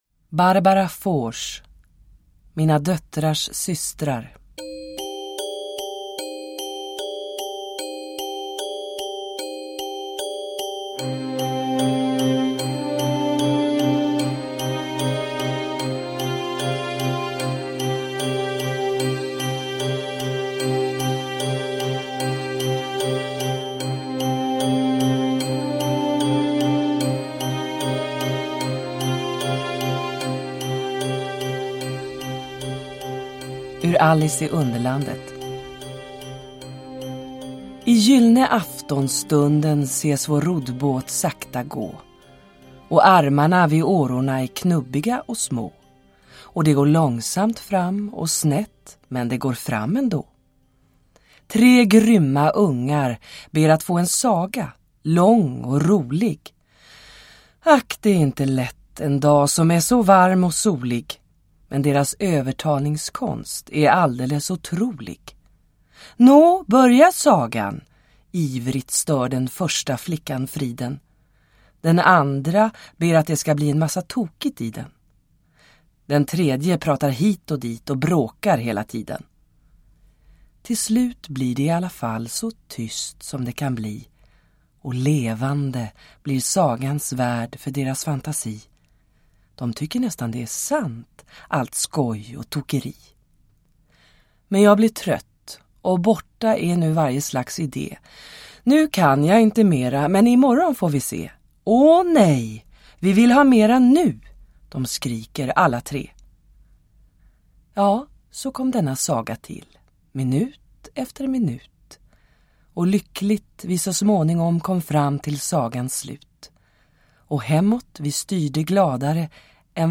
Mina döttrars systrar – Ljudbok – Laddas ner